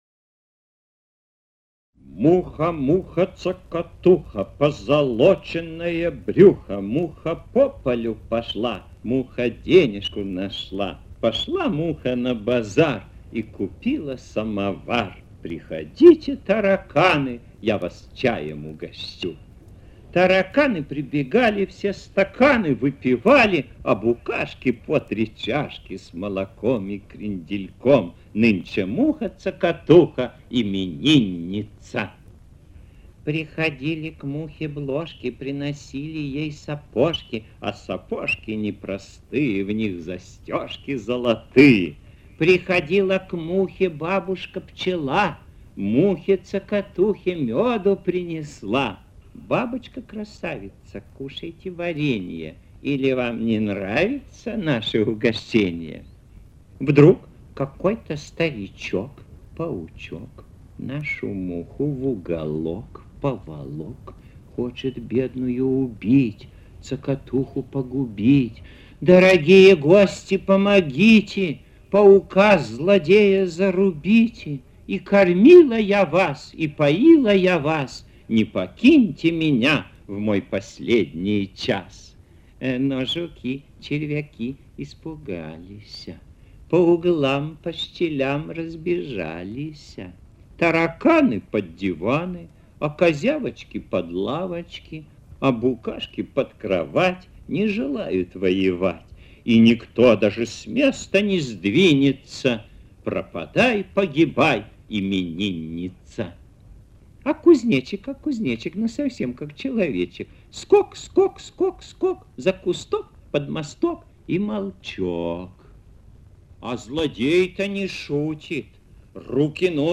АУДИОСКАЗКИ